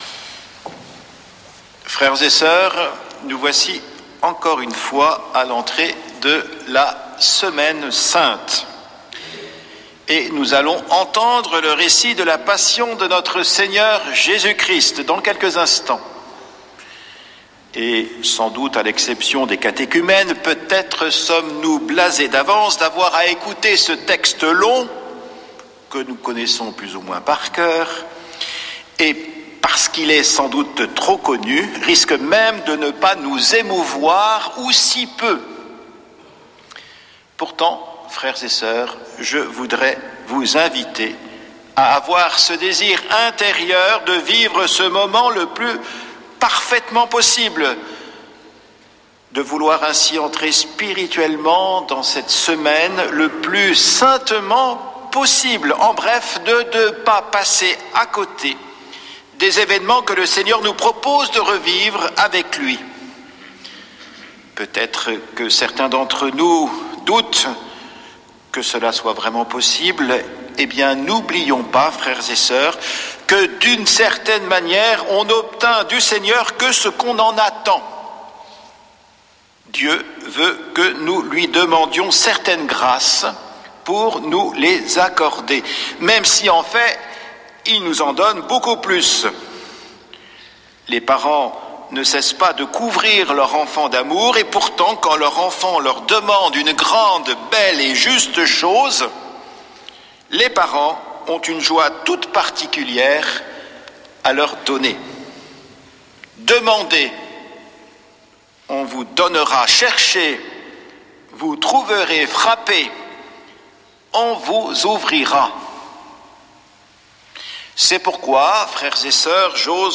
lundi 30 mars 2026 Messe depuis le couvent des Dominicains de Toulouse Durée 01 h 28 min